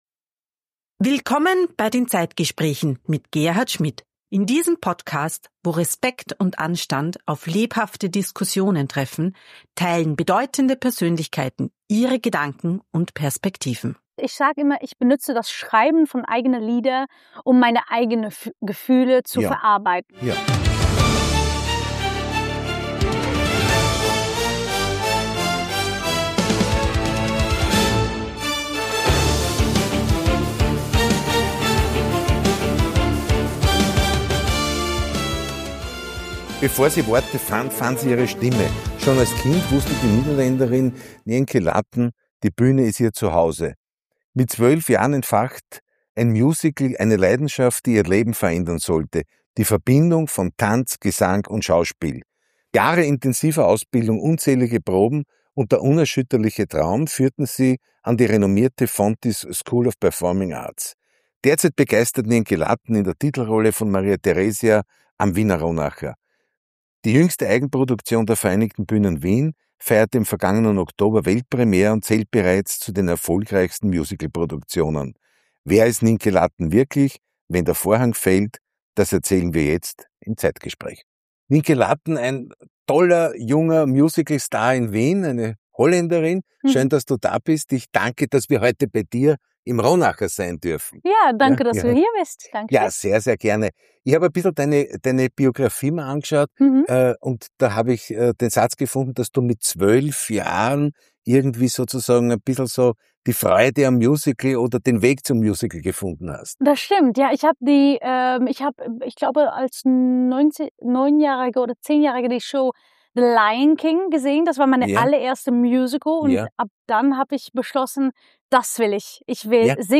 Zeit für Gespräche – Zeit für Antworten.